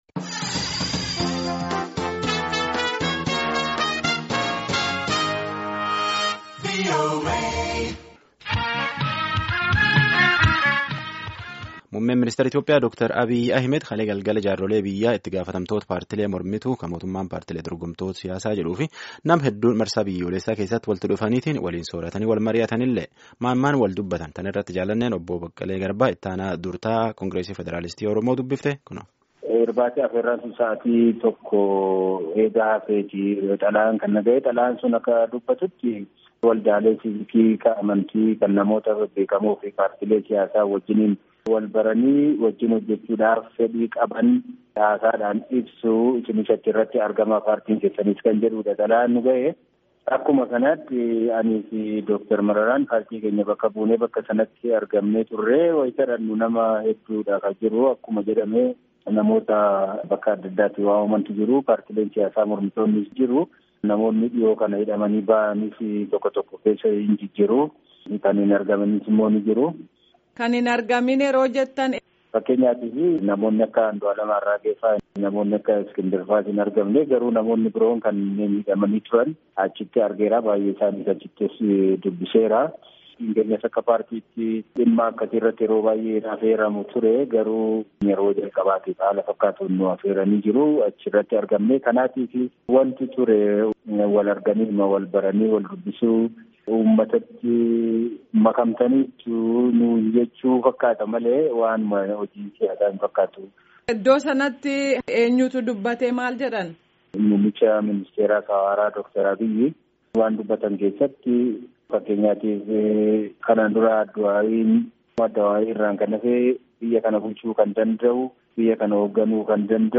Gaaffii fi Deebii